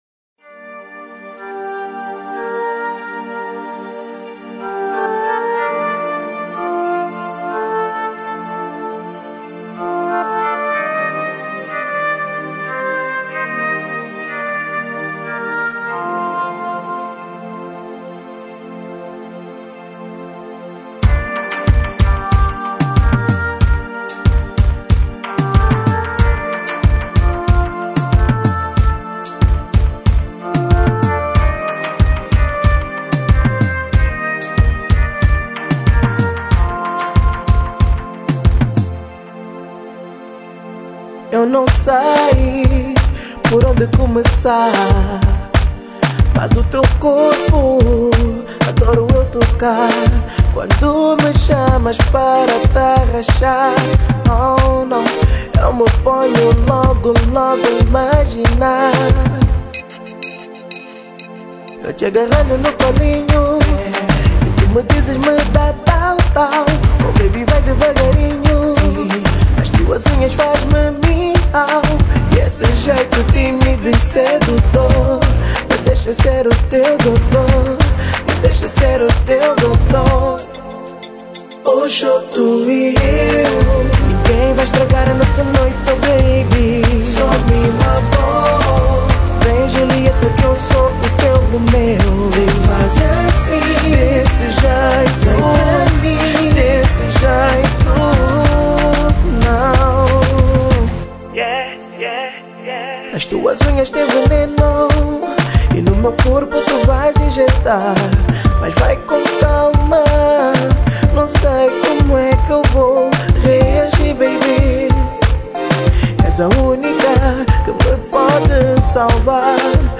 Kizomba Para Ouvir: Clik na Musica.